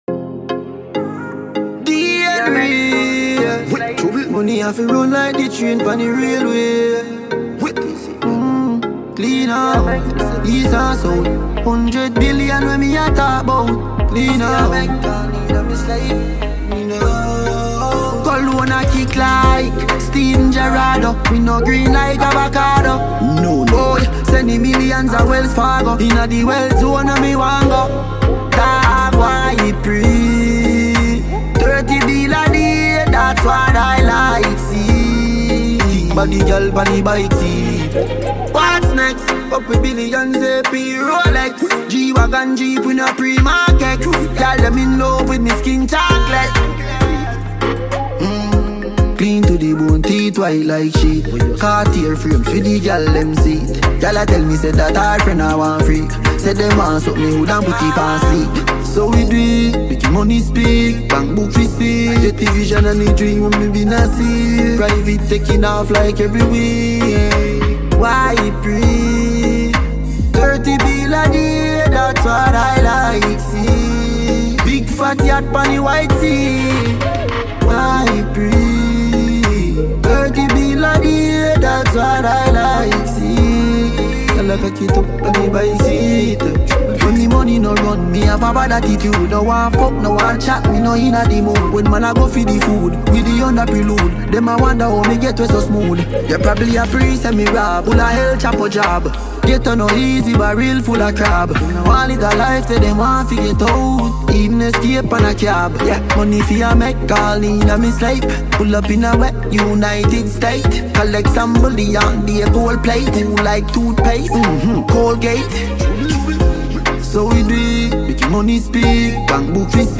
Jamaican dancehall musician